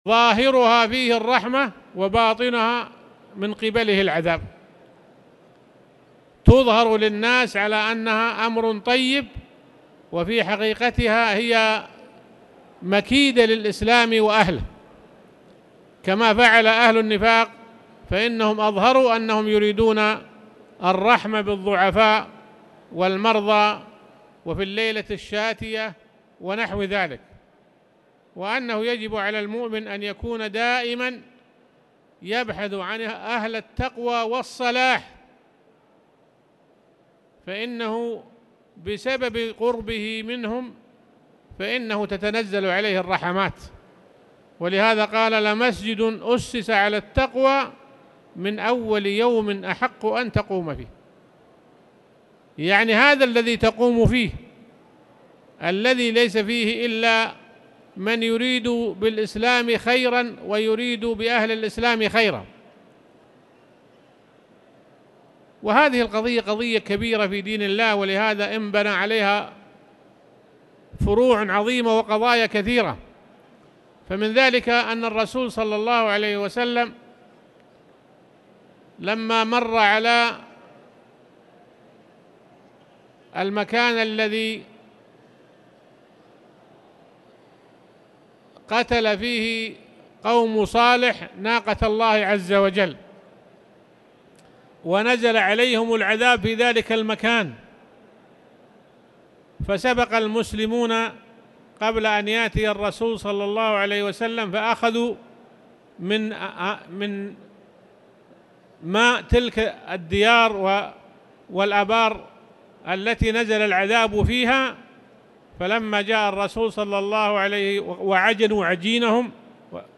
تاريخ النشر ٢٤ محرم ١٤٣٨ هـ المكان: المسجد الحرام الشيخ